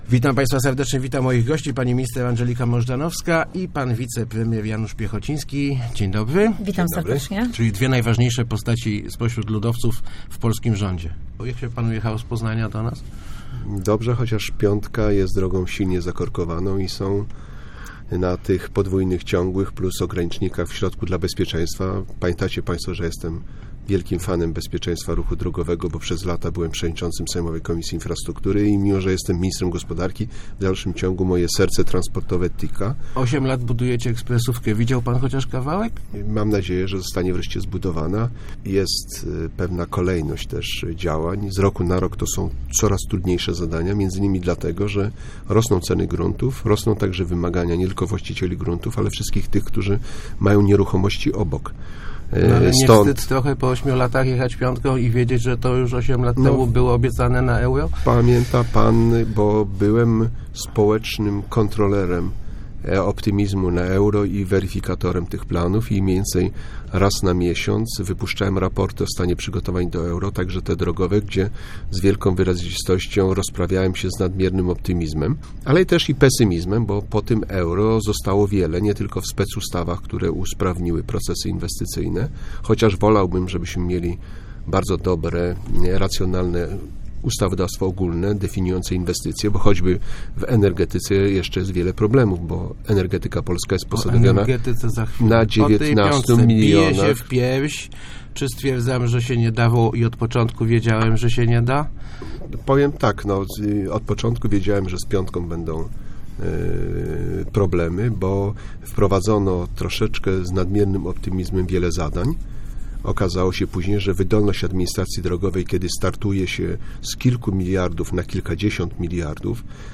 Prezes PSL w Radiu Elka. Piechociński: kopalni nie będzie